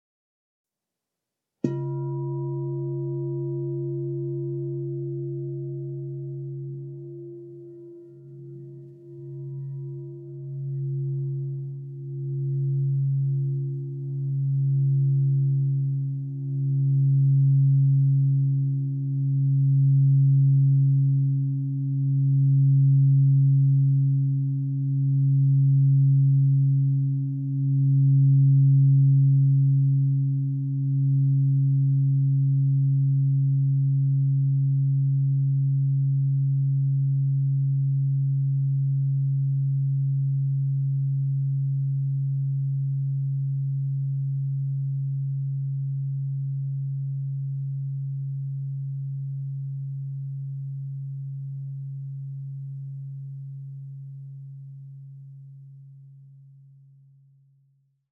Meinl Sonic Energy 16" white-frosted Crystal Singing Bowl Om 136,10 Hz (PCSB16OM)